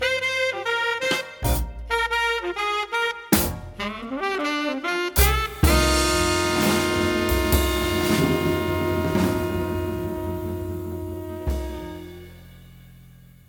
JAZZ SAMPLE PACK #0001- Download Here